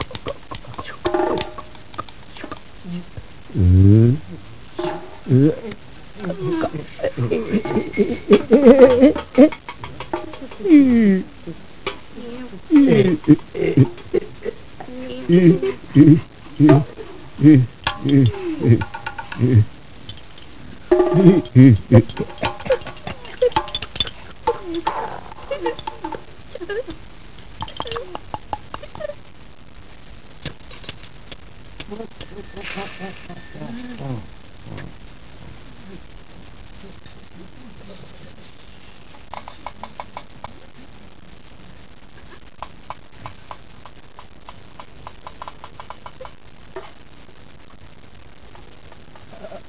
Registrazioni sonore di happening Fluxus
Performers: Jackson Mac Low e Anne Tardos